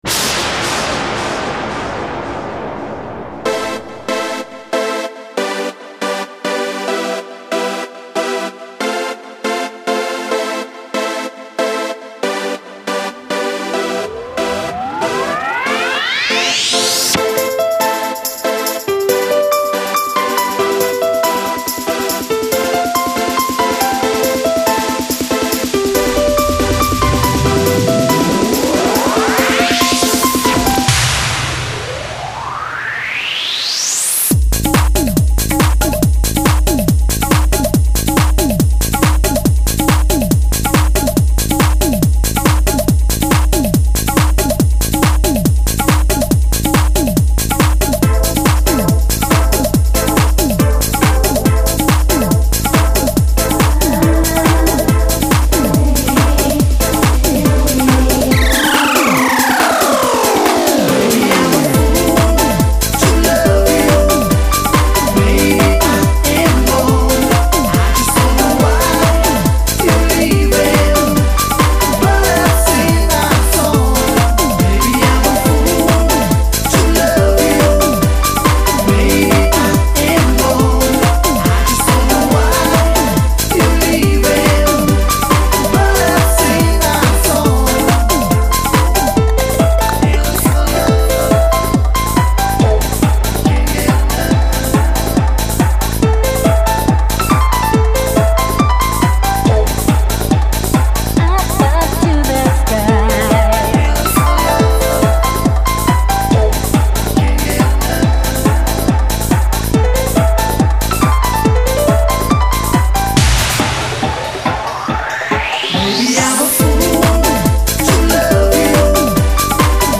Tags: music prank dj trance dnb